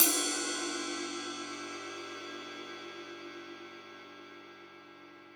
West MetroRide (2).wav